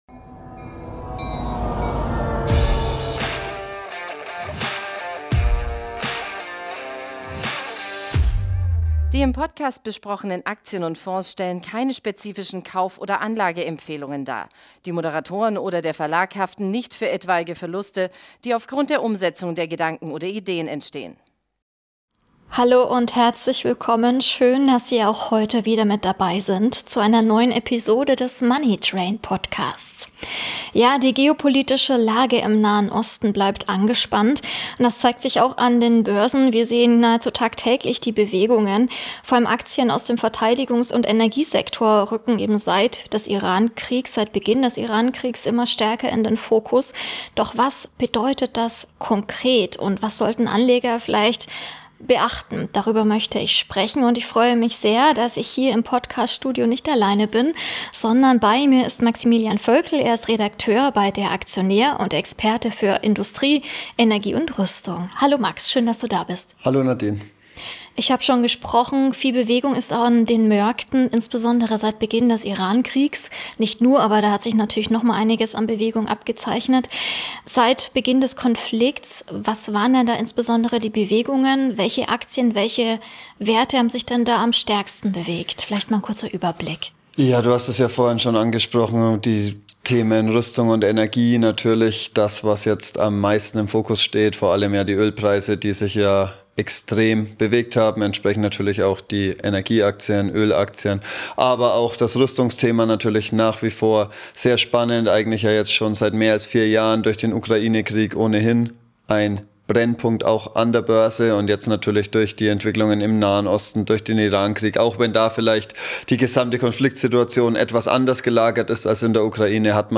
Interviewgast